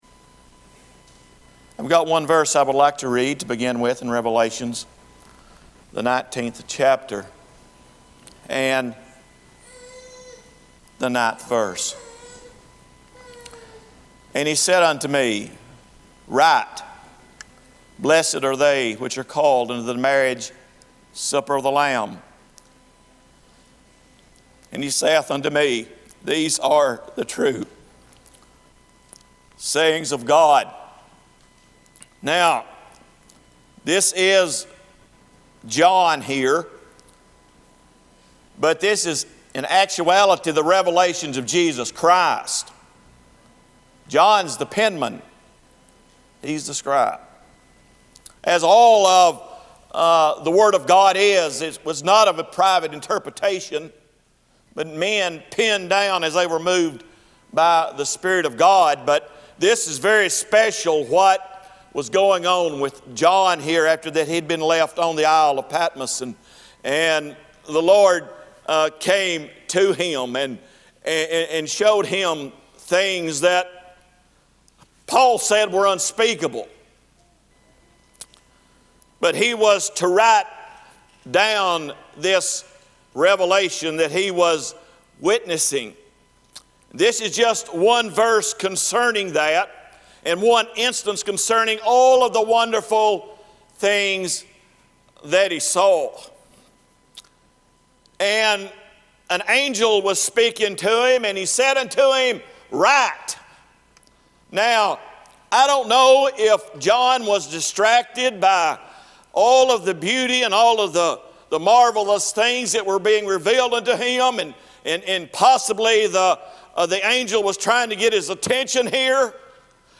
Monday Evening revival service from 2017-10-16 at Old Union Missionary Baptist Church in Bowling Green, Kentucky.